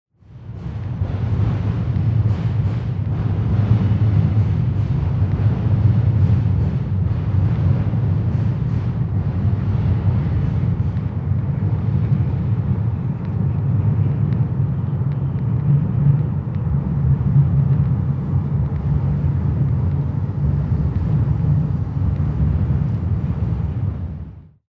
recorded in Portland, Oregon on July 2011